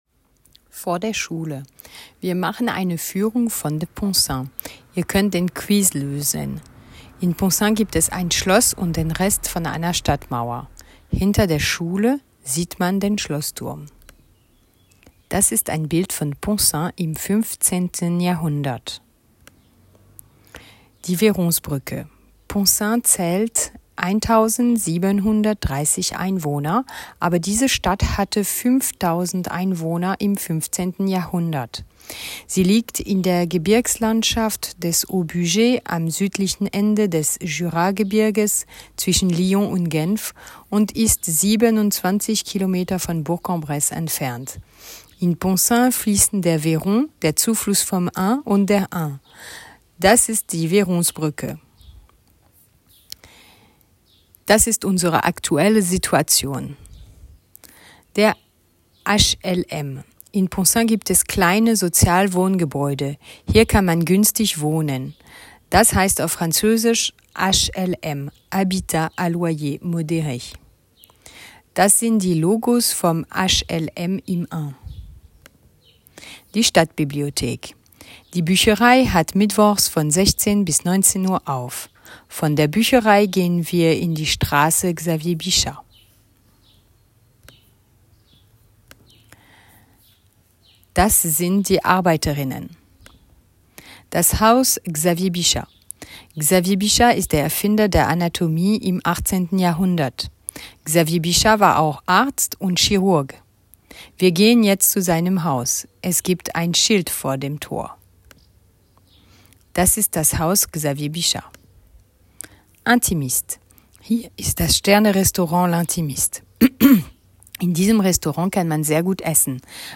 🏘 Pour les élèves de 5e qui feront la visite guidée lundi matin, vous avez en pièce-jointe une piste audio en allemand pour vous entrainer à la prononciation.